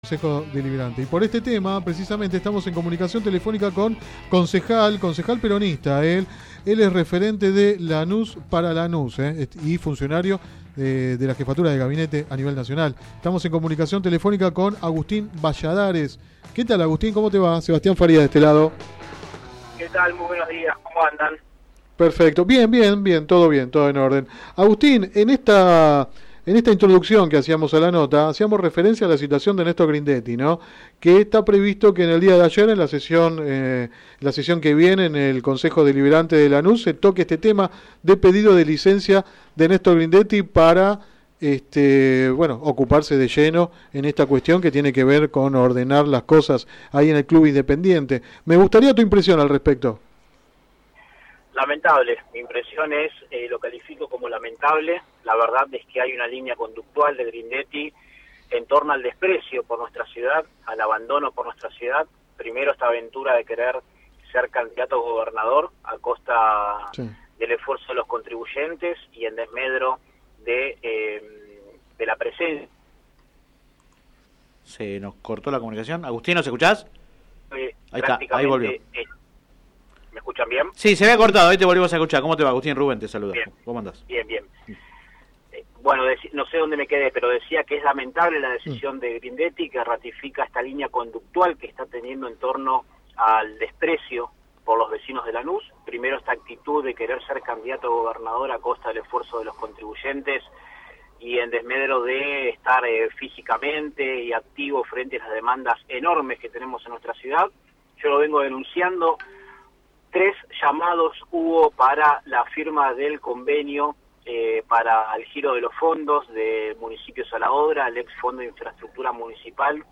El concejal del Frente de Todos -De Lanús para Lanús, hombre del Movimiento Evita, Agustín Balladares, -en entrevista en el programa radial Sin Retorno (lunes a viernes de 10 a 13 por GPS El Camino FM 90 .7 y AM 1260)- había anticipado su voto negativo al pedido de licencia por un mes parte del intendente Néstor Grindetti para trabajar en equilibrar la situación en el Club Atlético Independiente, entidad en la que asumirá la presidencia, y apuntó a la solicitud como «lamentable» y señaló la «falta del interés» del mandatario para con el vecino».